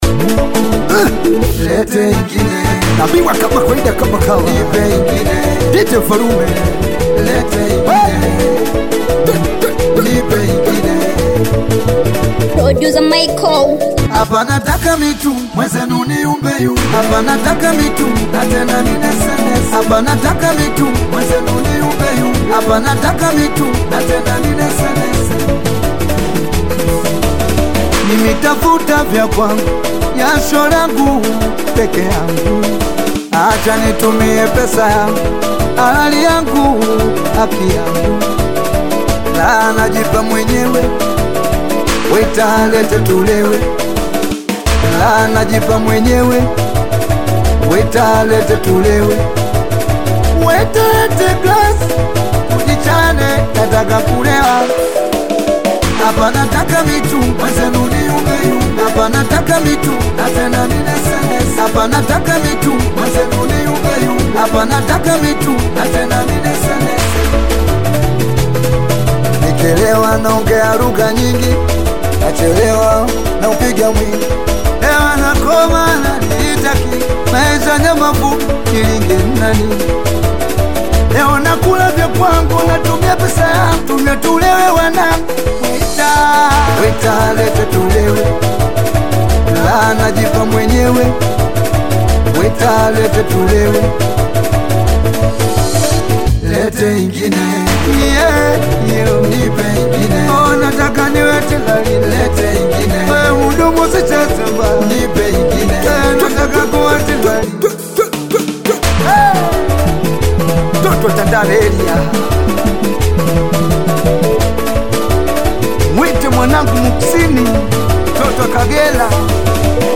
Tanzanian veteran Singeli artist, singer and songwriter
singeli song